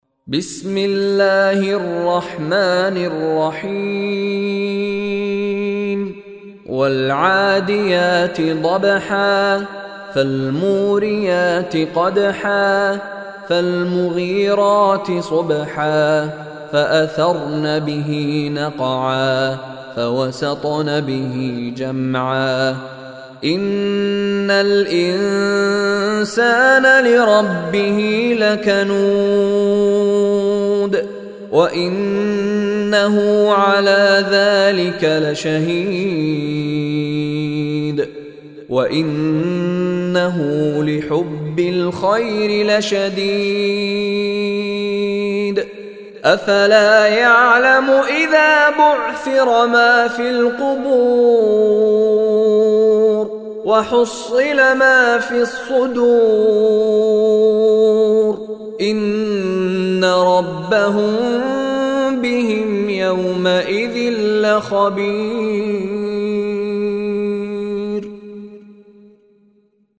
Surah Adiyat Recitation by Mishary Rashid Alafasy
Surah Adiyat, listen online mp3 arabic recitation in the voice of Sheikh Mishary Rashid Alafasy.